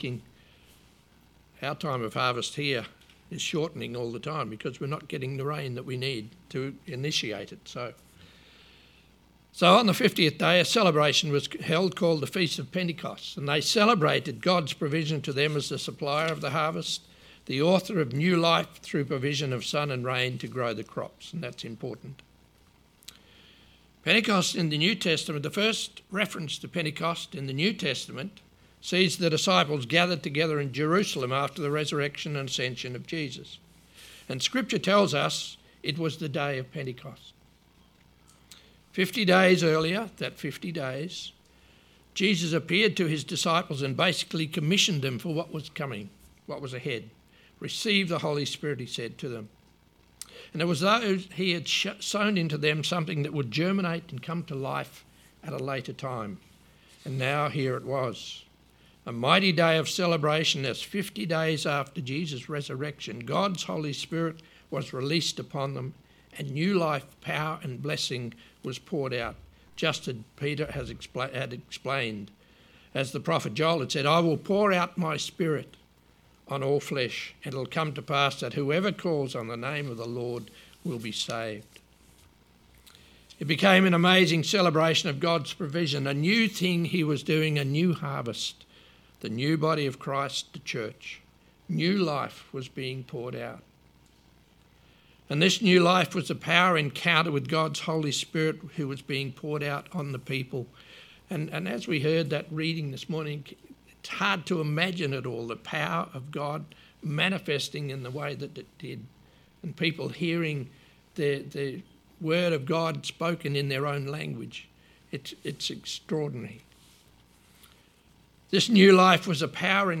Sermon 19th May – A Lighthouse to the community